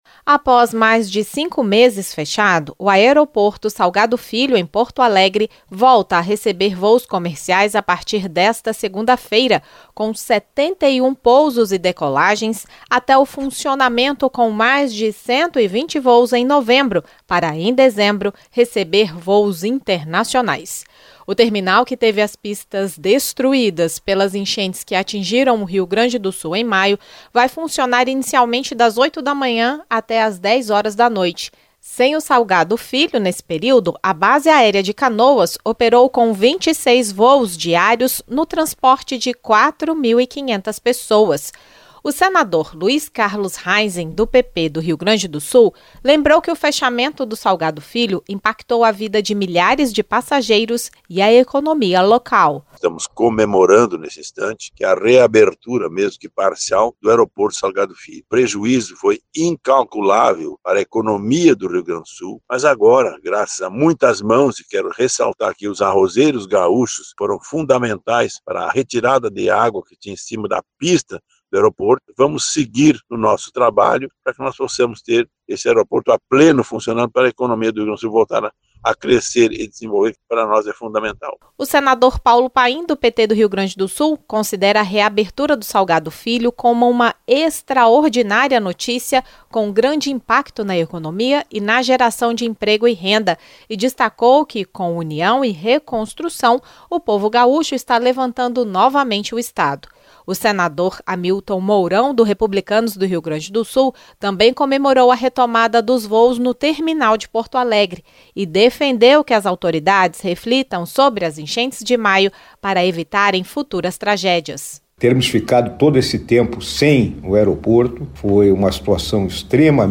A bancada gaúcha comemorou a reabertura do aeroporto Salgado Filho, em Porto Alegre, após cinco meses sem funcionamento em decorrência das enchentes que atingiram o Rio Grande do Sul em maio. Luis Carlos Heinze (PP-RS) destacou o empenho dos arrozeiros na retirada de água das pistas do terminal. Hamilton Mourão (Republicanos -RS) ressaltou a dificuldade dos moradores do estado e dos visitantes nesse período, o que impactou também no turismo.